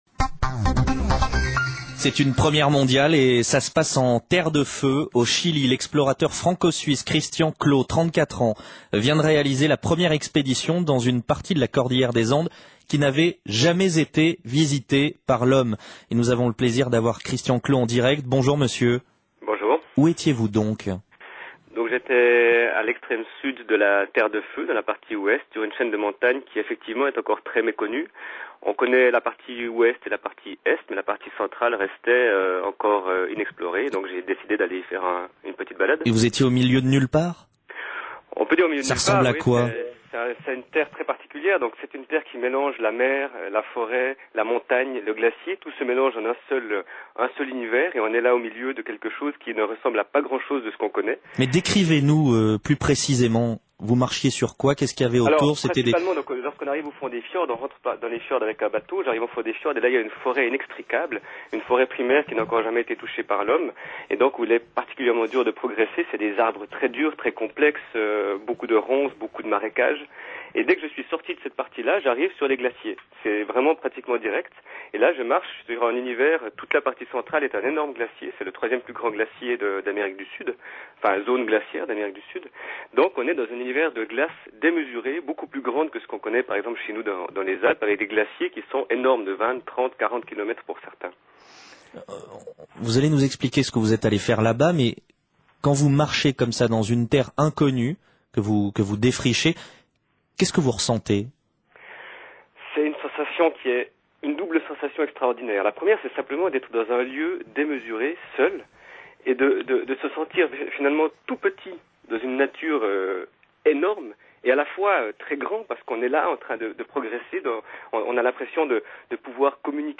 12H00 Le Journal en direct